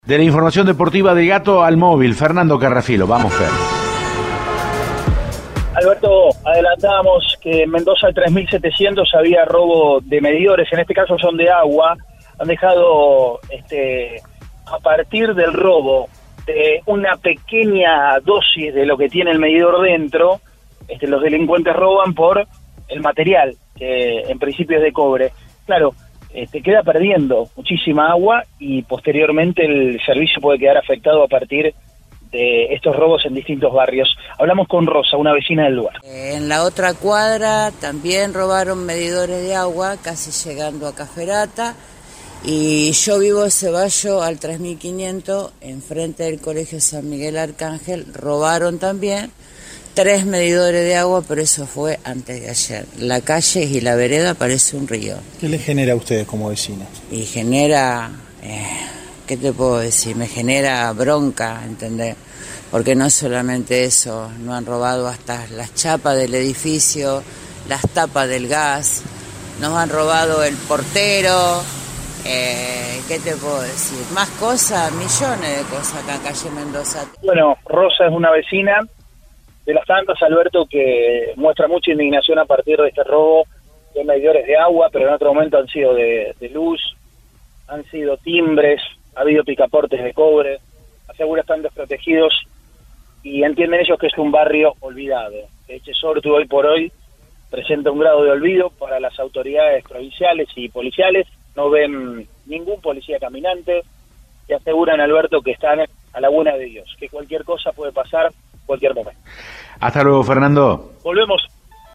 Una vecina de la zona, en contacto con el móvil de Cadena 3 Rosario, en Siempre Juntos, explicó lo que sucede: “En la otra cuadra también robaron medidores de agua. La calle y la vereda parecen un río. Genera bronca, porque no es solamente eso: han robado las chapas del edificio, el portero, las tapas de gas”.